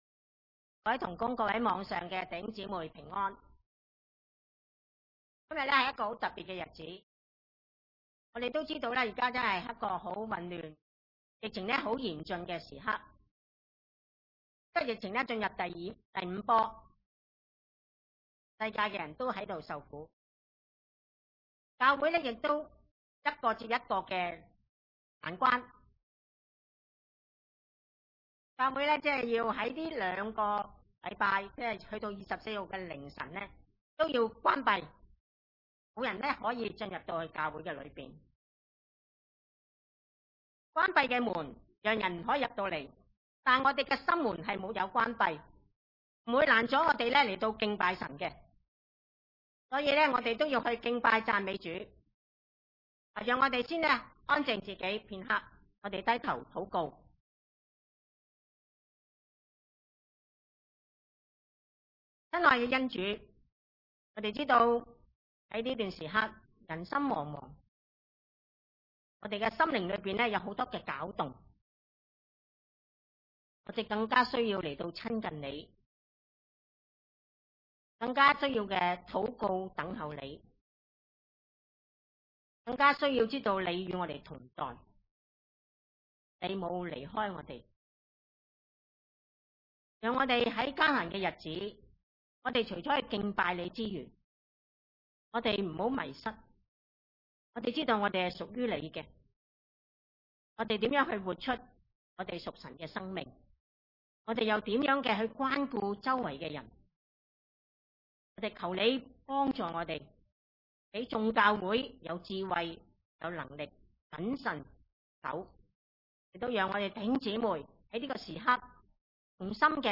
王上18：1-16 崇拜類別: 主日午堂崇拜 1過了許久，到第三年，耶和華的話臨到以利亞說：「你去，使亞哈得見你；我要降雨在地上。」